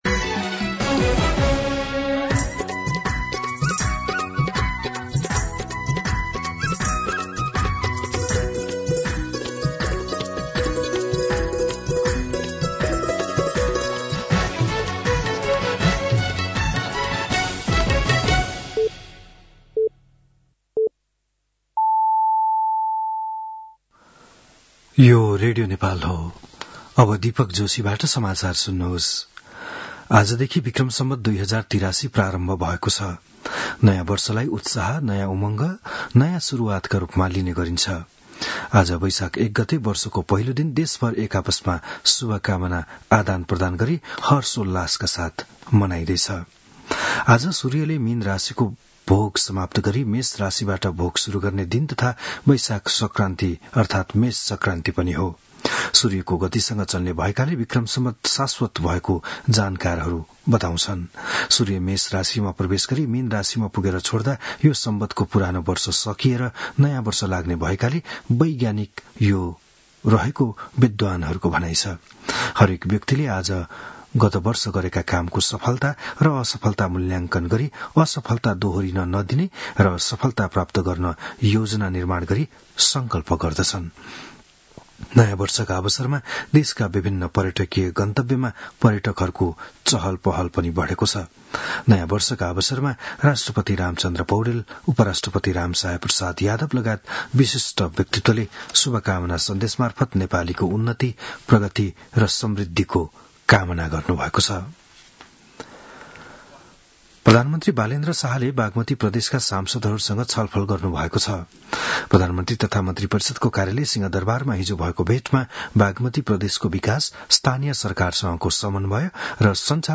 बिहान ११ बजेको नेपाली समाचार : १ वैशाख , २०८३